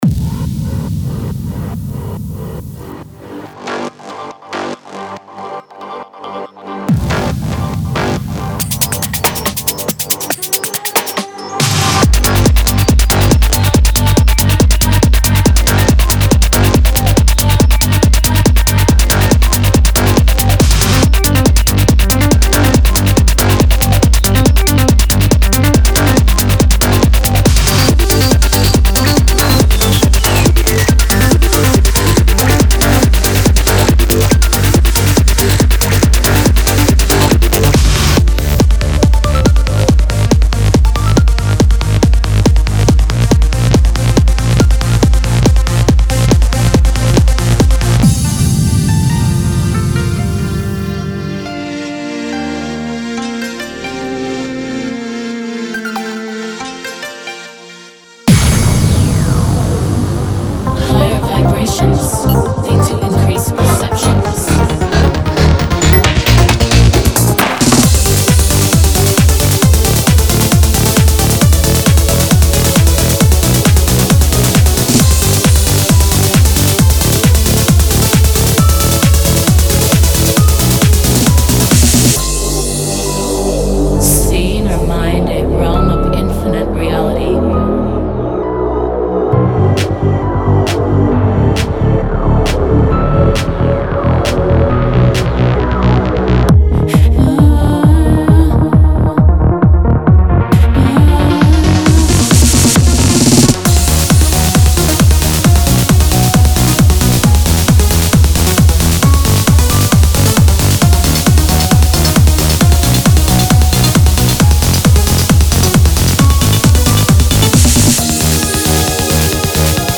Type: Serum Samples
Psy-Trance Trance Uplifting Trance